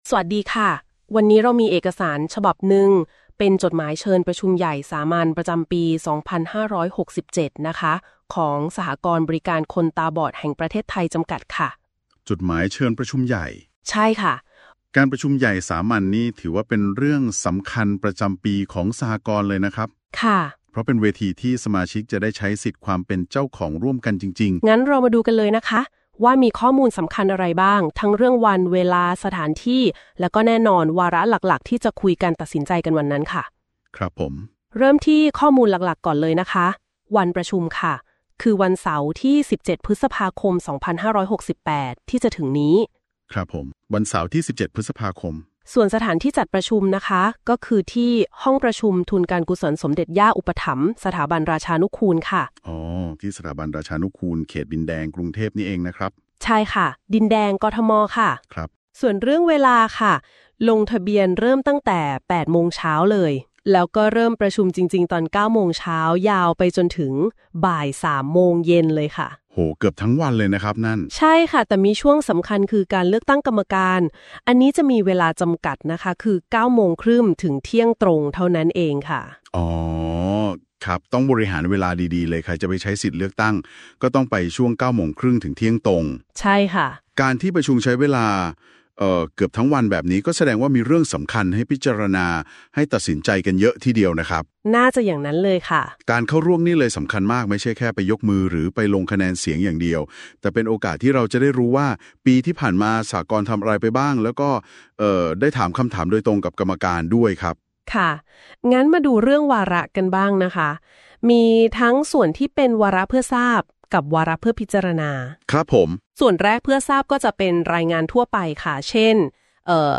รายงานการประชุมใหญ่สามัญประจำปี2562